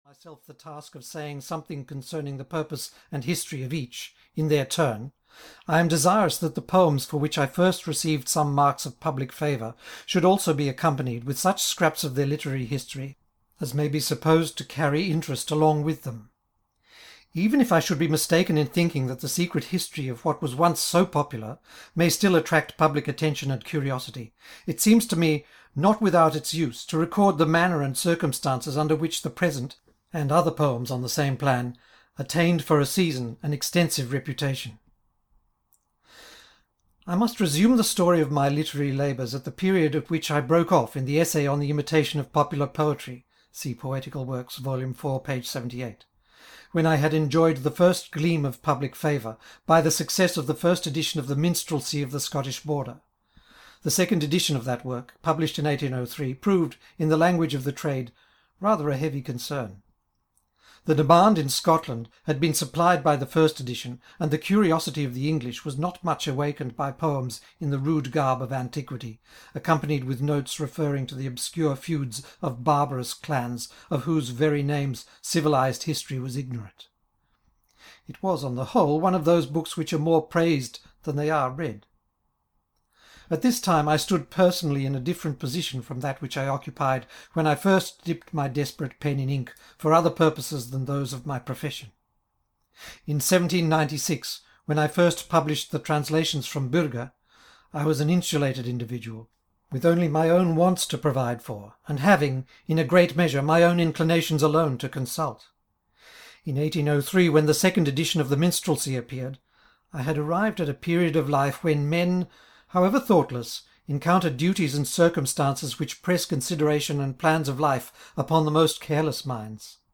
The Lay of the Last Minstrel (EN) audiokniha
Ukázka z knihy